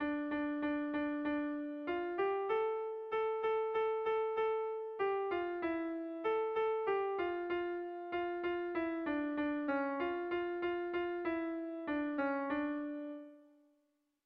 Sehaskakoa
ABD